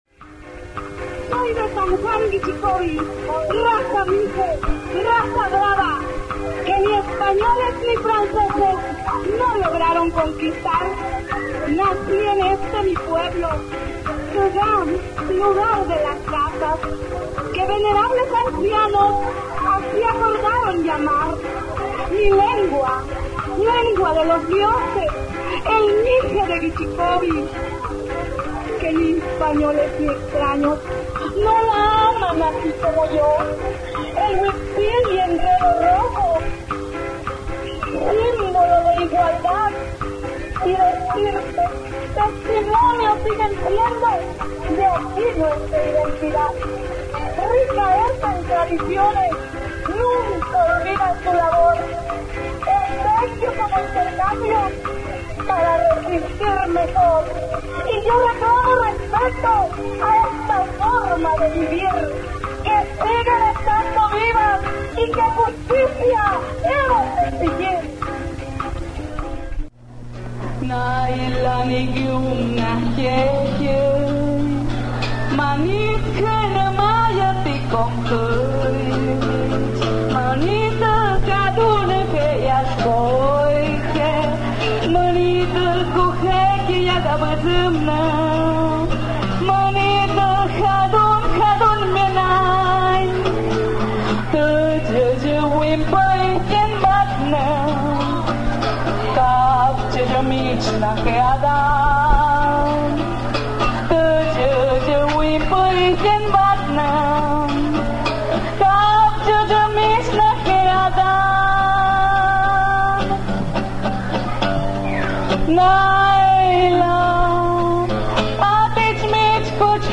§musica mixe
cancion_mixe_en_resistencia_desde_guichicovi_oaxaca.mp3